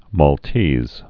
(môl-tēz, -tēs)